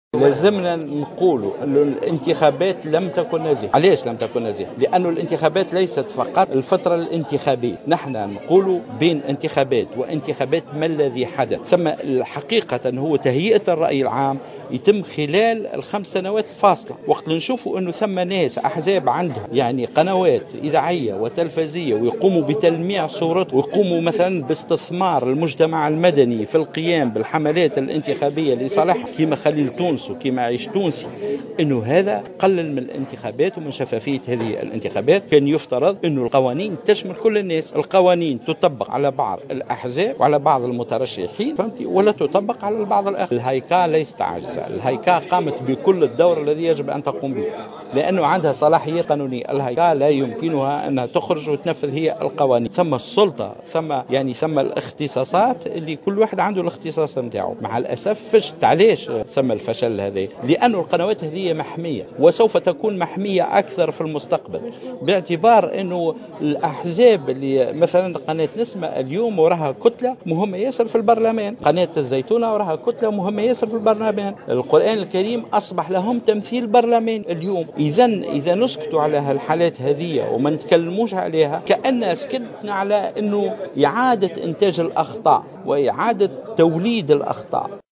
وأشار "السنوسي" في تصريح للجوهرة "أف أم" خلال ندوة صحفية عقدتها الهيئة بتونس العاصمة ، إلى أنّ التقرير الذي أعدّته "الهايكا" يتعلق ببعض القنوات غير القانونية التي استغلت هذه الفضاءات للقيام بحملات انتخابية والإشهار السياسي ، معبرا عن استغرابه من إنفاذ القانون على السيد سعيد الجزيري عن حزب الرحمة والتغاضي عن قناتيْ نسمة والزيتونة.